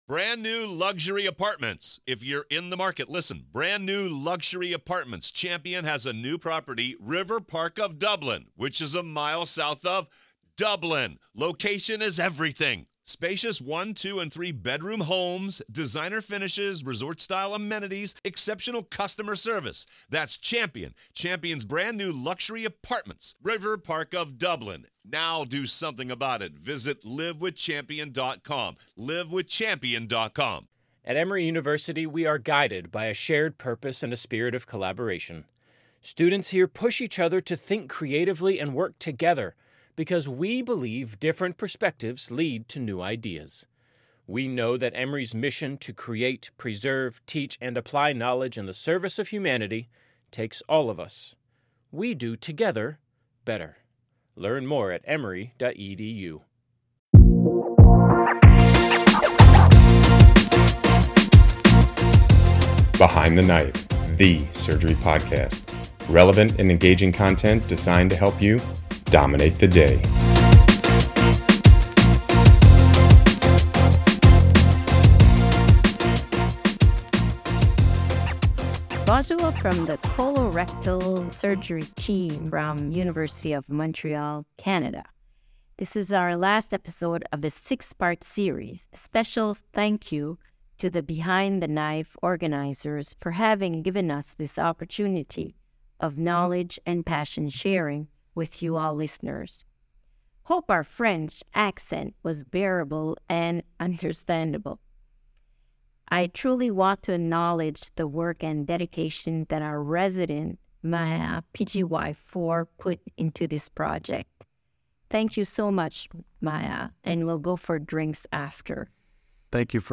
Behind The Knife: The Surgery Podcast